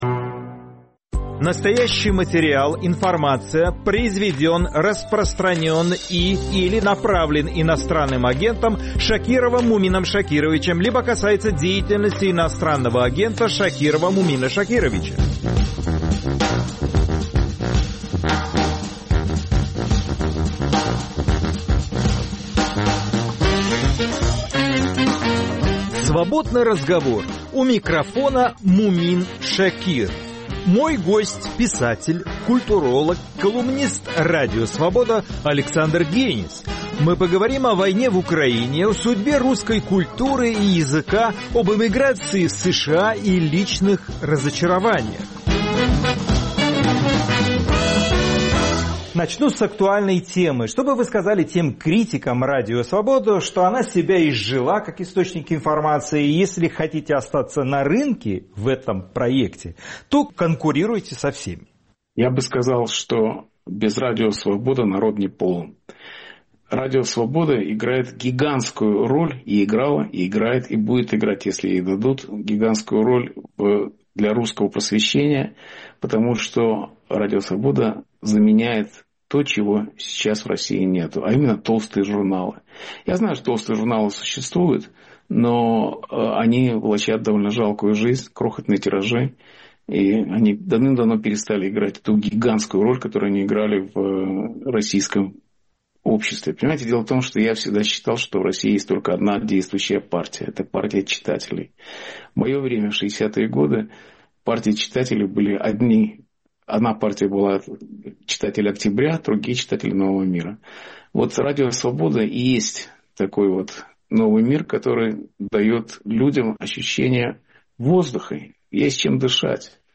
Свободный разговор с писателем Александром Генисом